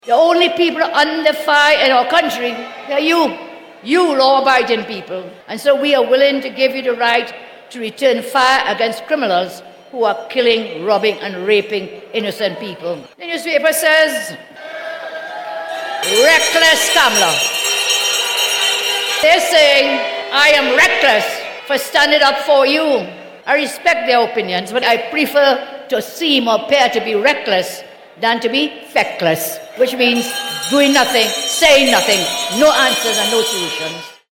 Speaking last night at a cottage meeting in San Fernando, Mrs Persad-Bissessar referred to her call for homeowners confronted by bandits to “light them up” with their licensed firearms.